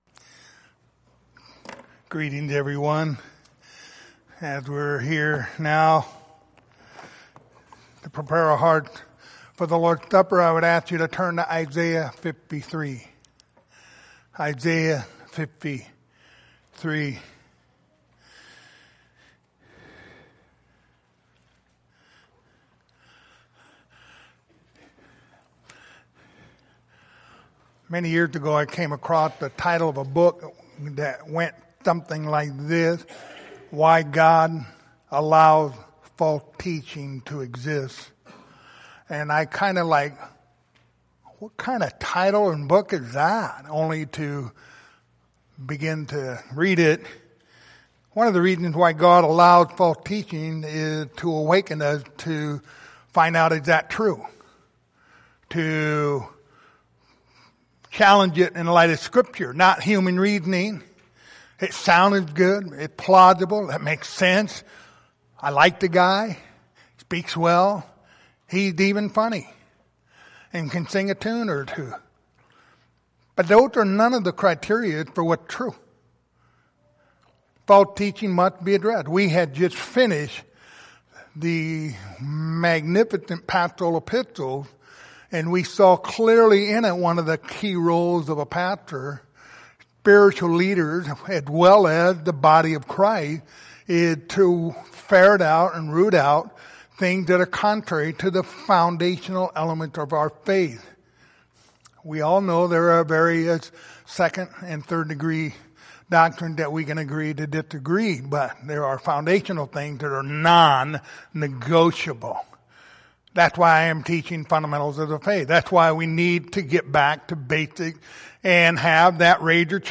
Isaiah 53:4-12 Service Type: Lord's Supper Topics